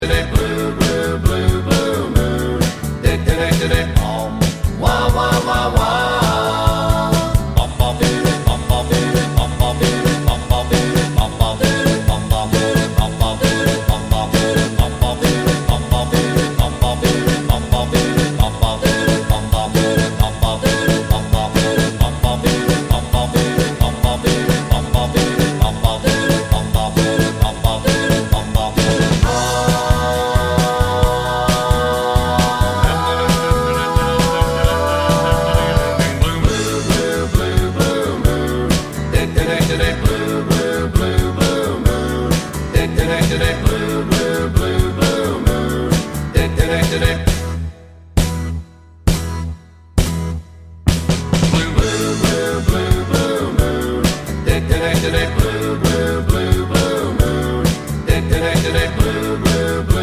Lead And Bassman Mix Rock 'n' Roll 2:20 Buy £1.50